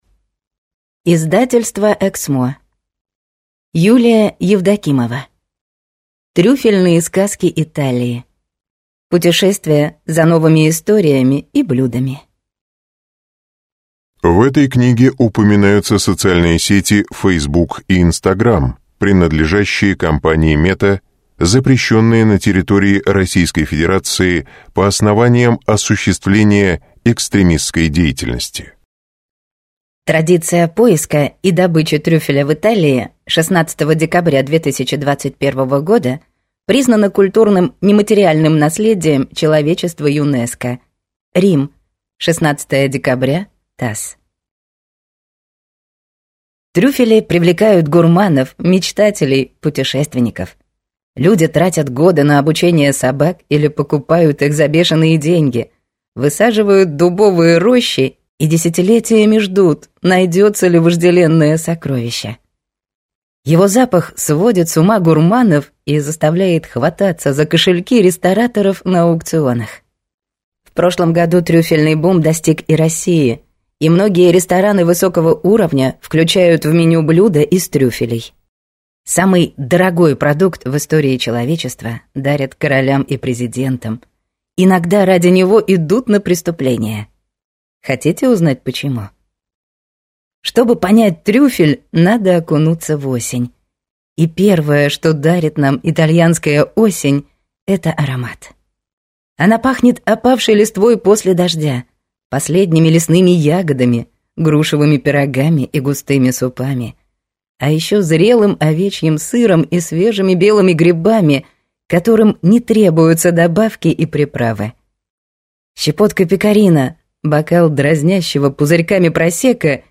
Аудиокнига Трюфельные сказки Италии. Путешествие за новыми историями и блюдами | Библиотека аудиокниг